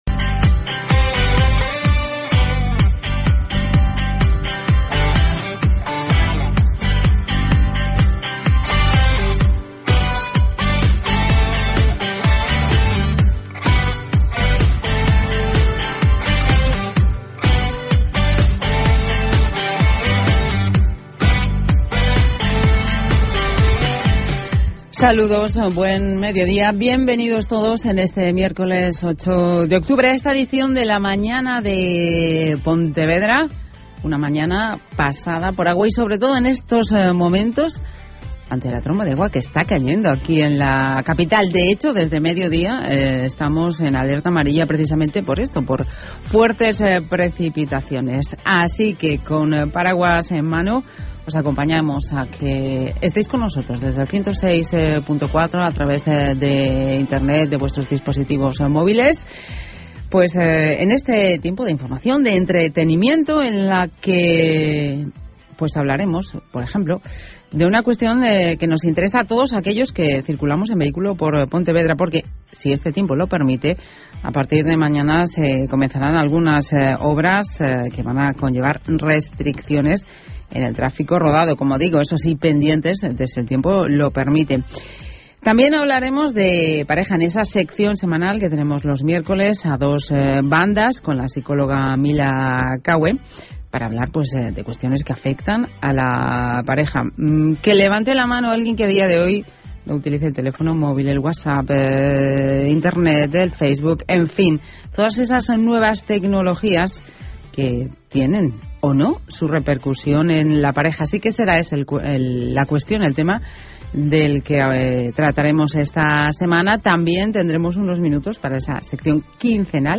Radio: Podcast COPE Pontevedra, sobre relaciones y nuevas tecnologías. 8 Octubre 2014